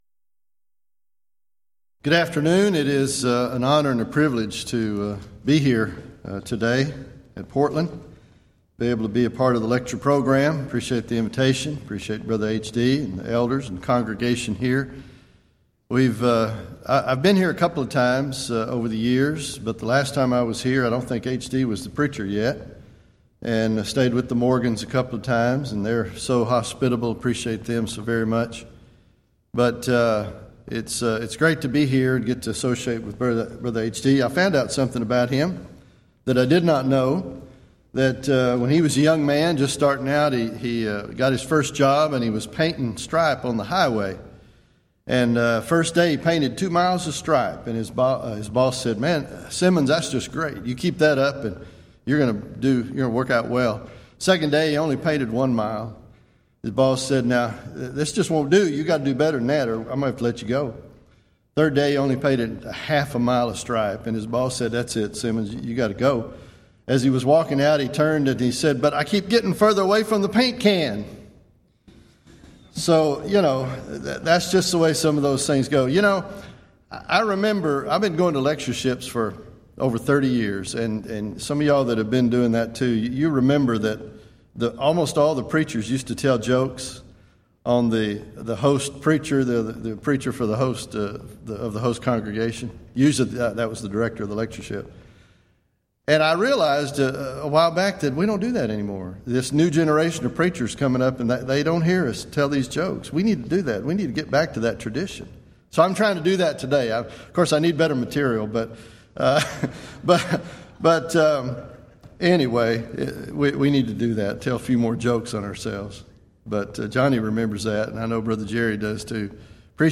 Event: 21st Annual Gulf Coast Lectures
lecture